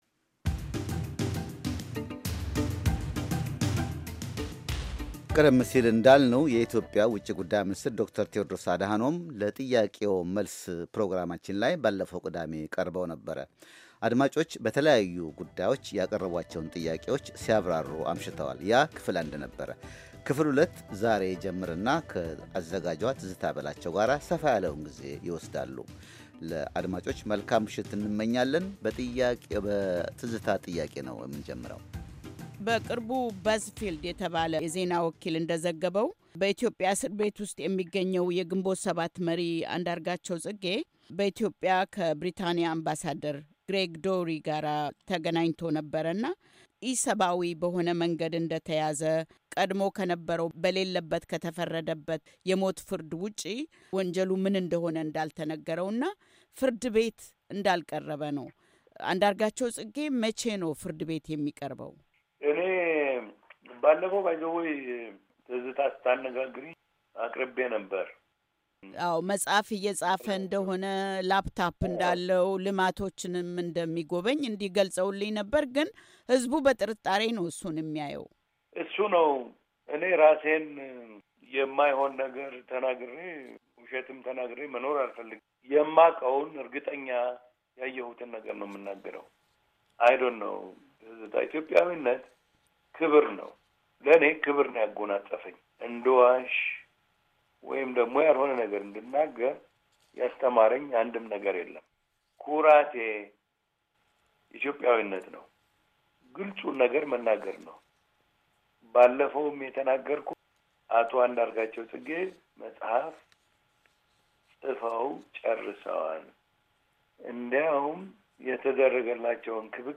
ክፍል 2፡ የኢትዮጲያ ውጭ ጉዳይ ሚኒስትር ለአሜሪካ ድምፅ ራድዮ የሰጡት ቃለ-ምልልስ